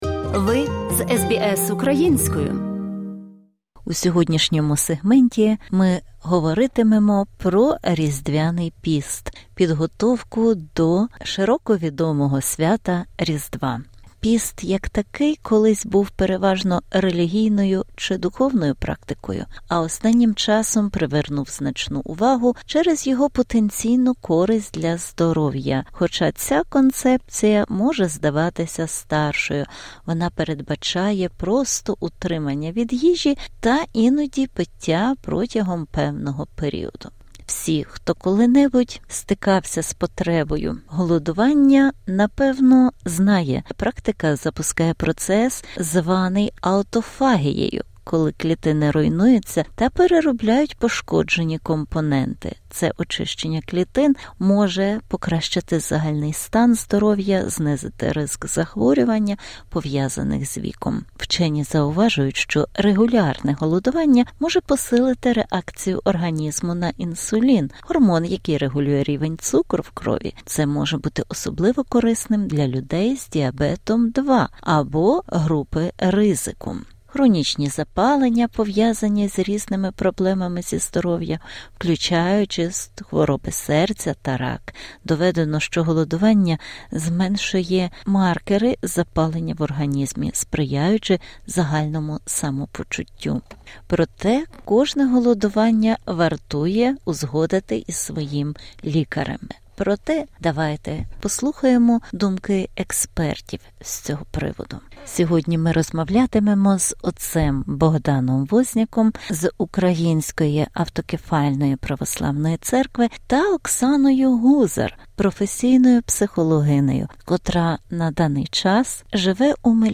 У цьому інтерв’ю